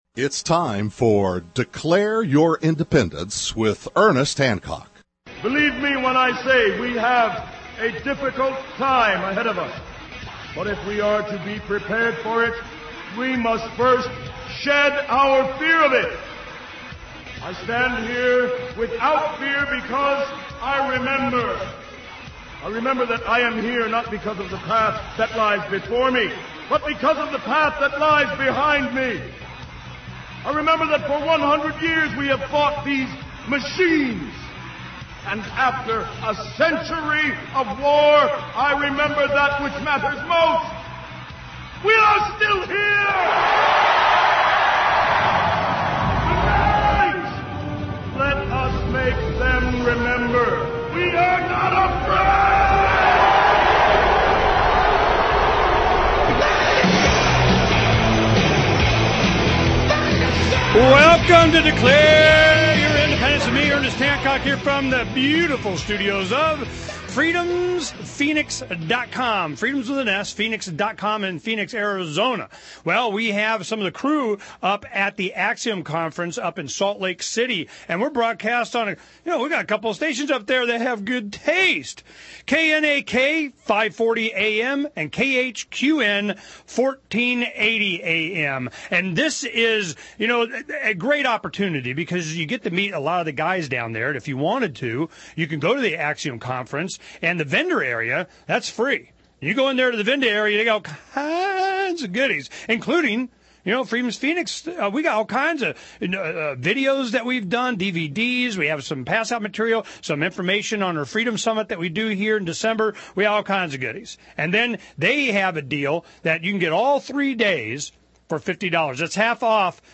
(Via Live Audio Feed)